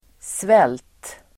Uttal: [svel:t]